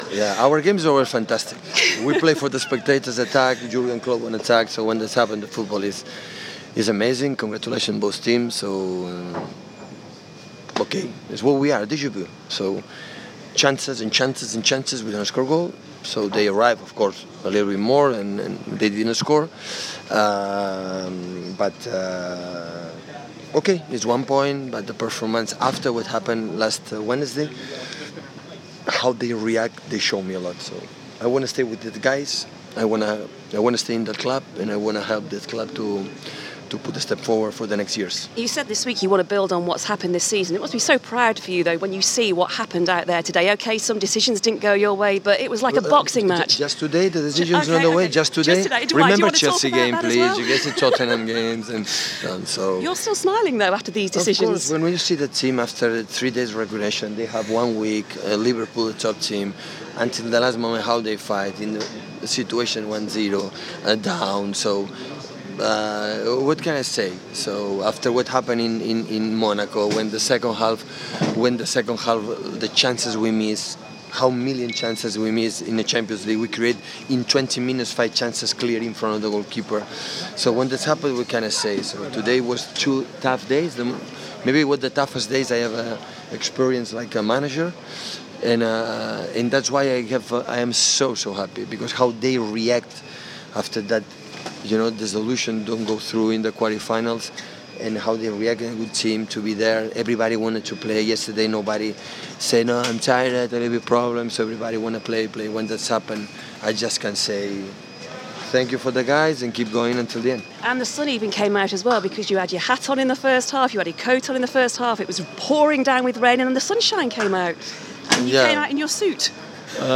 Manchester City manager Pep Guardiola has his say following the 1-1 draw with Liverpool in the Premier League.